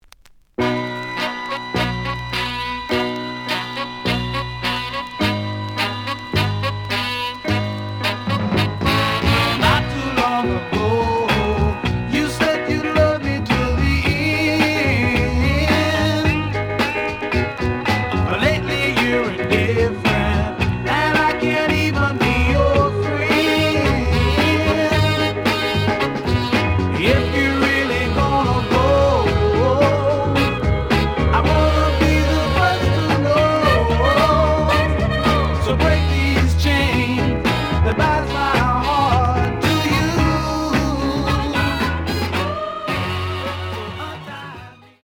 The audio sample is recorded from the actual item.
●Format: 7 inch
●Genre: Soul, 60's Soul